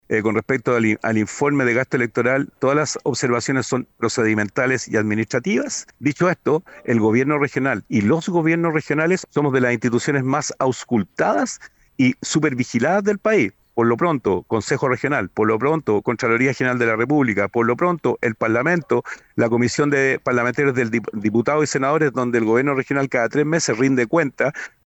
Sin embargo, Rodrigo Mundaca, en conversación con La Radio, aseguró que todo está en regla y que el informe solo entrega recomendaciones, afirmando que es imposible incurrir en ilegalidades en una de las instituciones más supervigiladas del país.